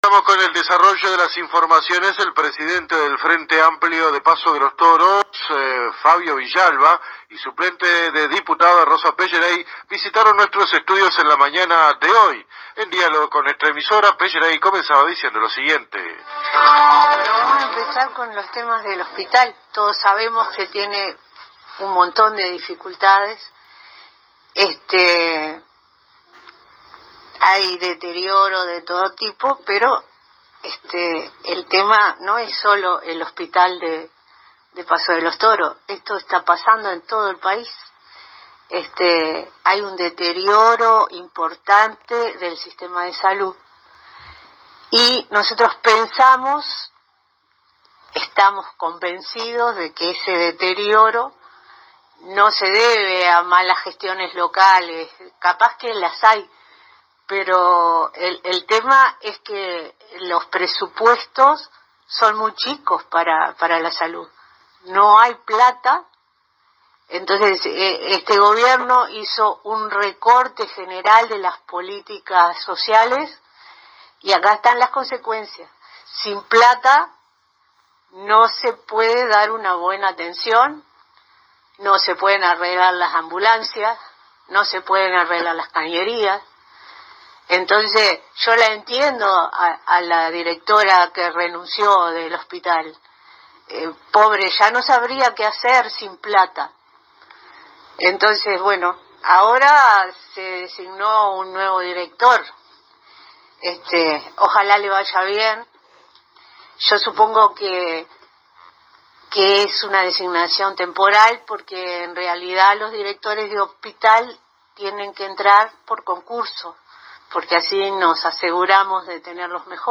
Fuente: AM 1110 Radio Paso de los Toros